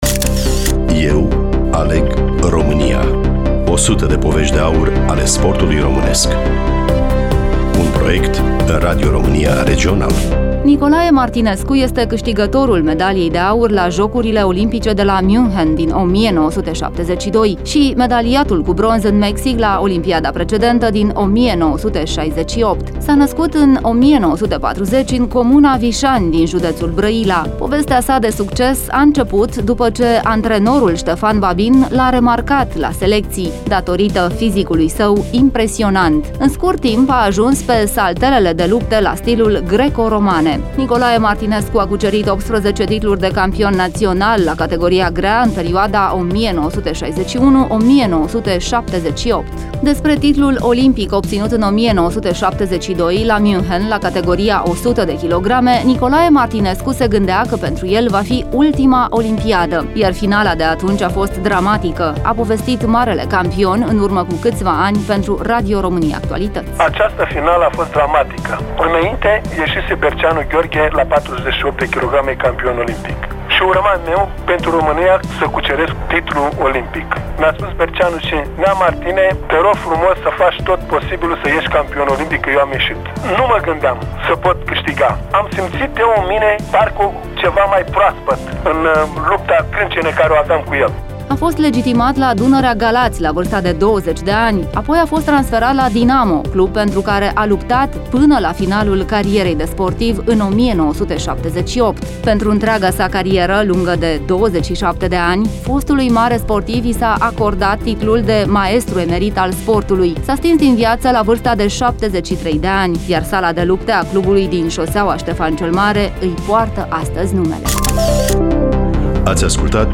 Studioul Radio România Constanţa